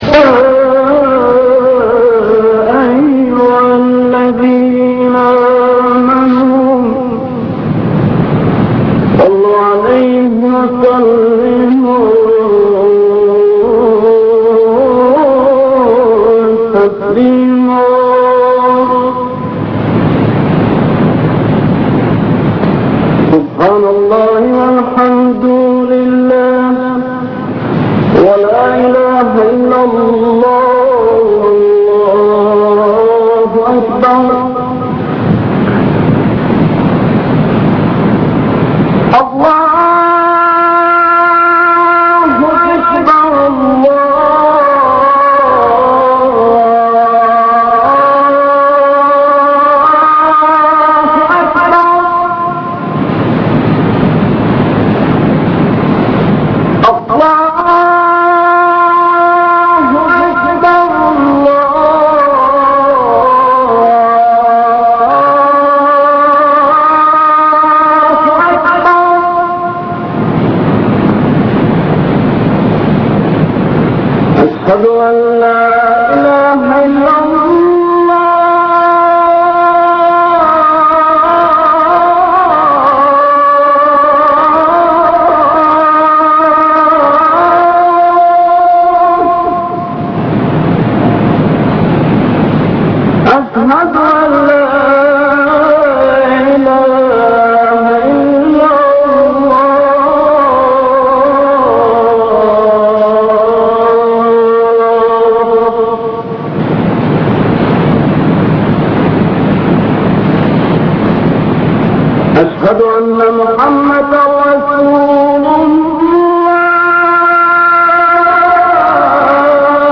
adhan1.ram